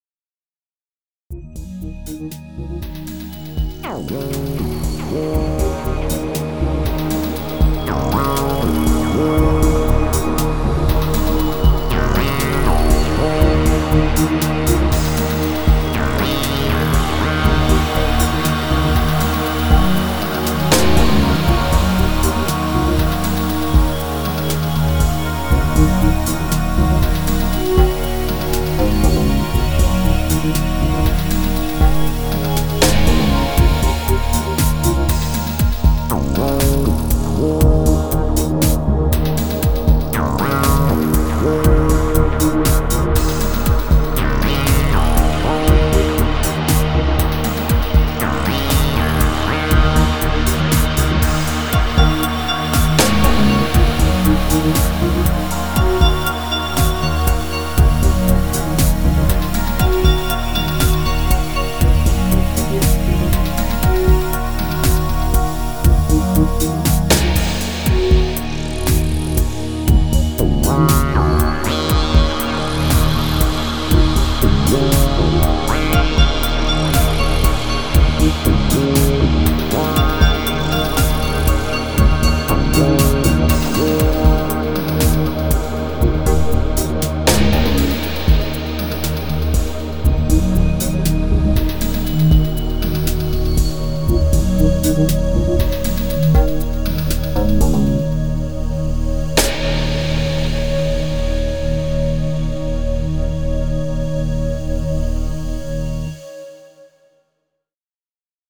Genre Ambient